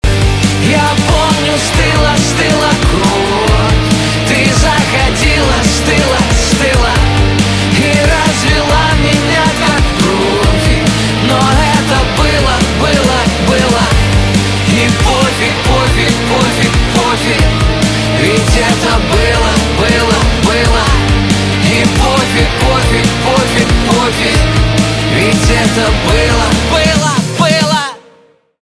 русский рок , гитара , барабаны
чувственные